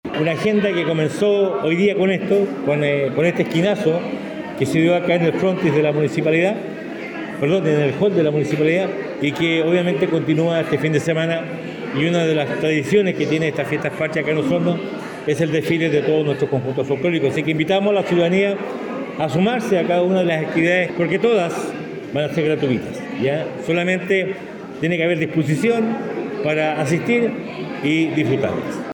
También se invita a la comunidad a participar  del tradicional Desfile de Conjuntos Folclóricos, como parte del Programa de Fiestas Patrias que desarrolla el municipio local, donde participarán más de 30 agrupaciones de la ciudad, como lo explicó el Alcalde Emeterio Carrillo.